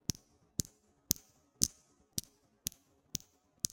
描述：点击计算机键盘上的键
标签： 键盘 MTC500-M002-S14 输入 打字
声道立体声